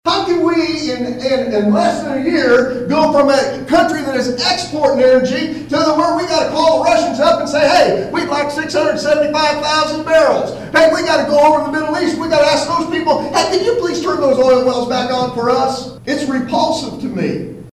Republican House & Senate candidates speak in Atlantic Monday evening
(Atlantic, Iowa) – Cass County Republicans hosted a “Know Your Candidates” forum Monday evening, at the Cass County Community Center in Atlantic.